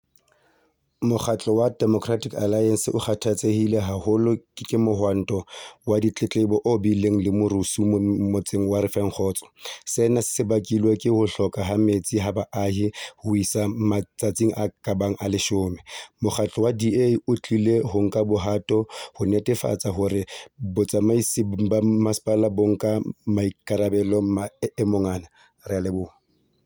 Sesotho soundbites by Cllr Thulani Mbana